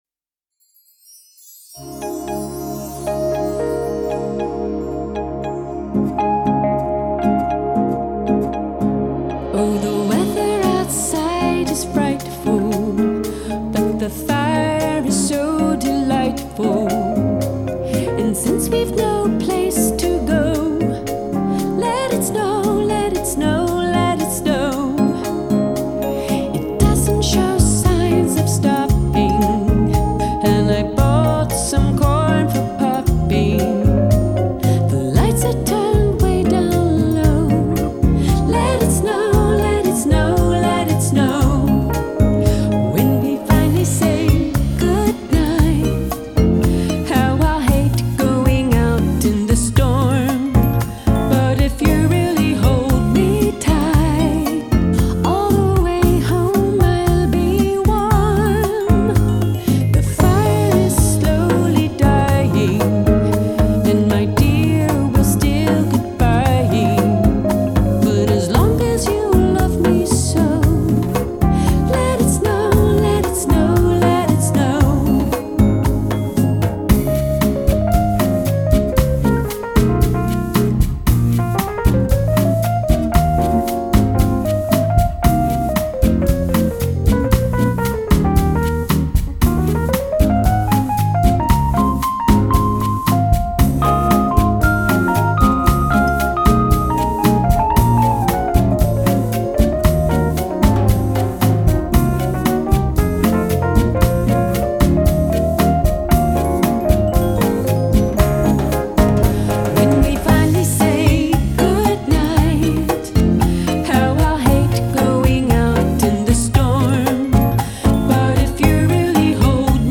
Genre: Bossa Nova Christmas